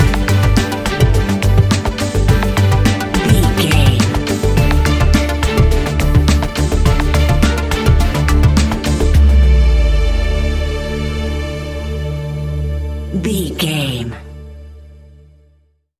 Ionian/Major
D♭
electronic
techno
trance
synths
synthwave